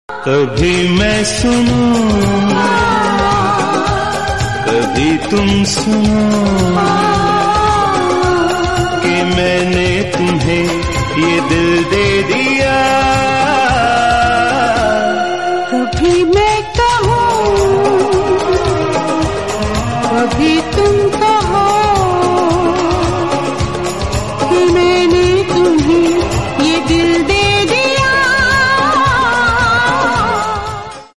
Bollywood 4K Romantic Song